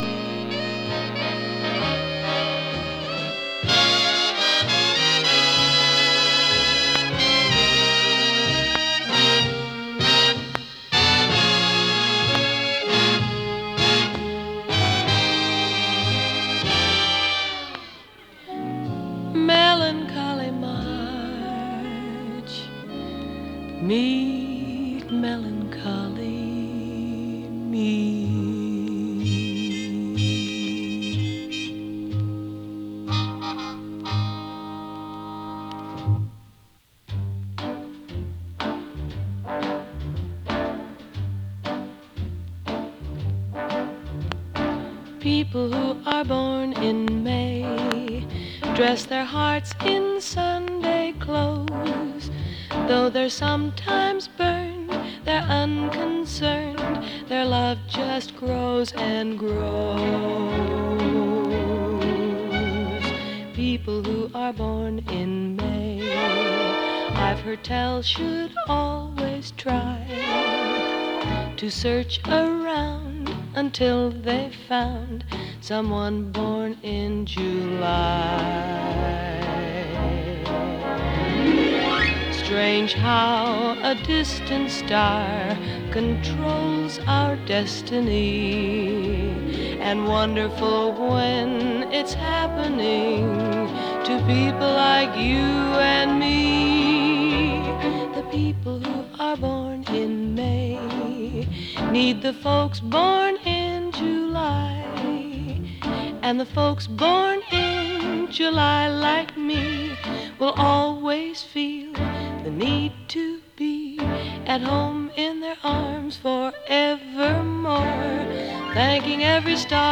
カリフォルニアはサンタローザ出身の白人シンガー。
所々軽いパチ・ノイズ。